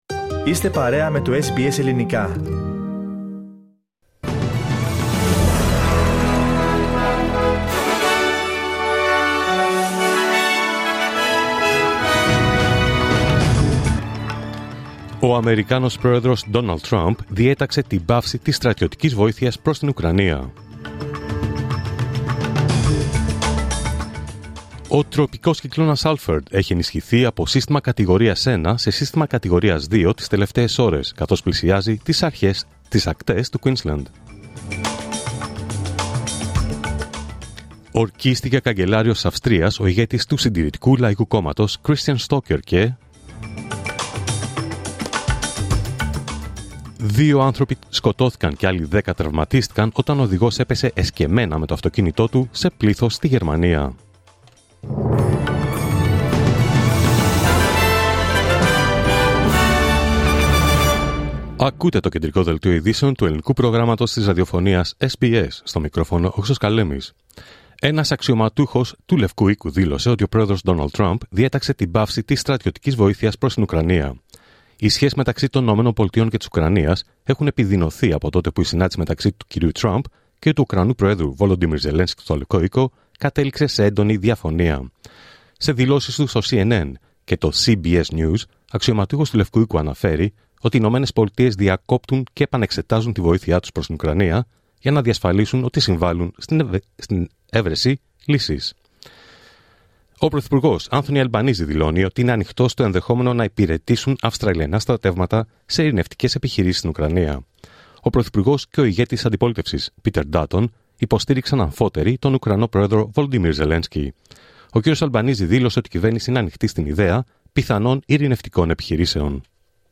Δελτίο Ειδήσεων Τρίτη 4 Μαρτίου 2025